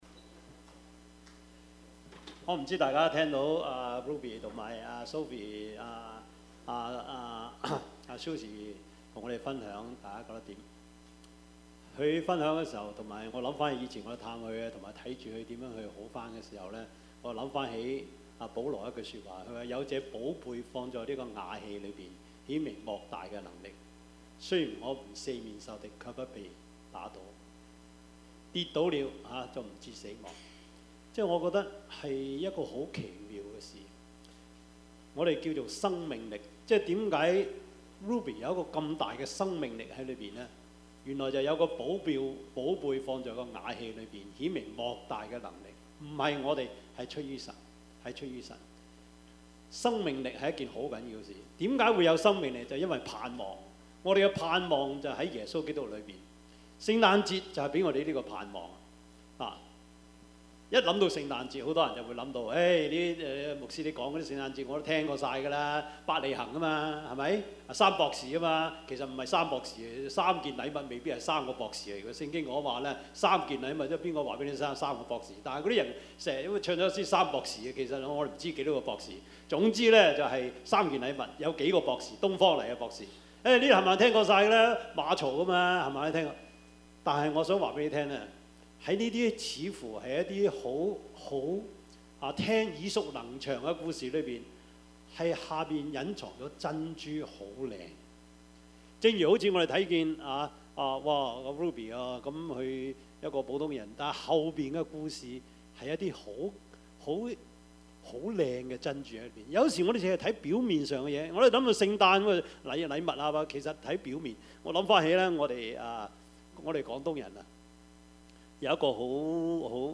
Passage: 馬太福音 2:1-12 Service Type: 主日崇拜
Topics: 主日證道 « 第一首聖誕歌 我是誰？